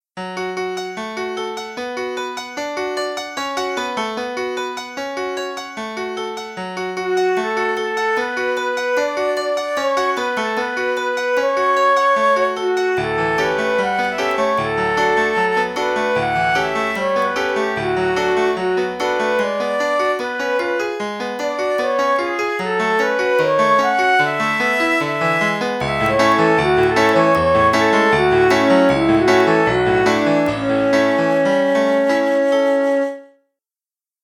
かすかに春の情景を描写した曲。
クラシック